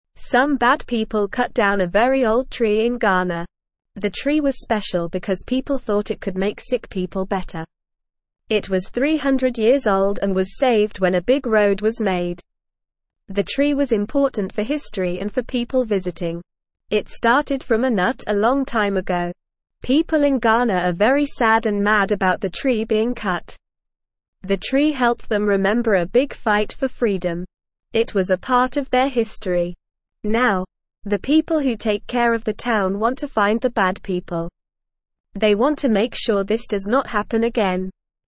Normal
English-Newsroom-Beginner-NORMAL-Reading-Old-Tree-in-Ghana-Cut-Down-by-Bad-People.mp3